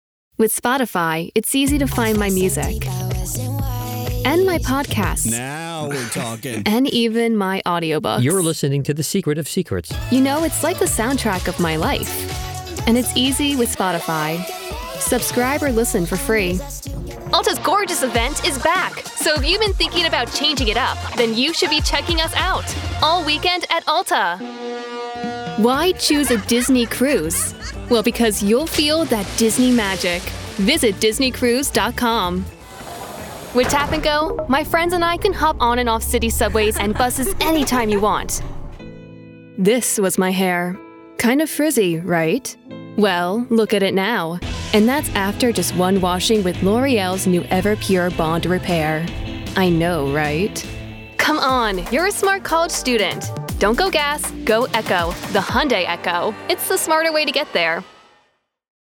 That’s why we focus on realistic mixing and clear, confident performances.
Here are some professional voice over demos we recorded
Genre: Commercial